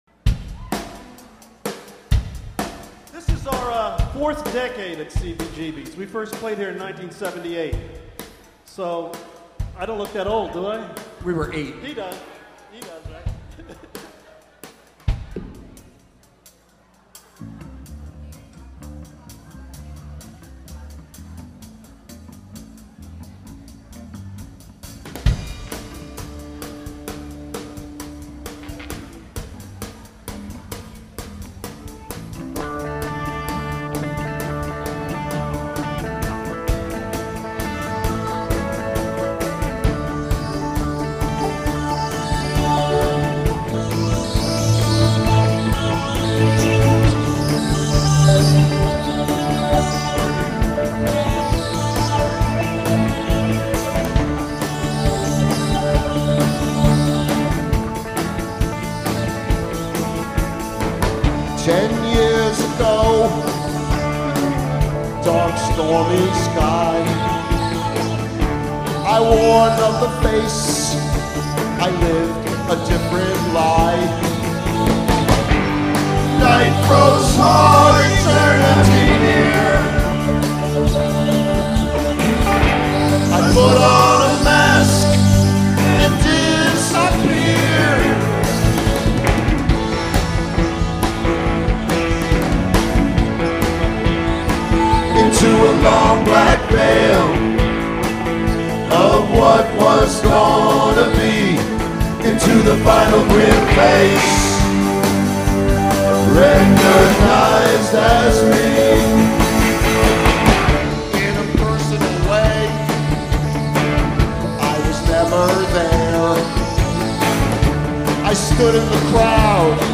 The last gig
cbgb 2006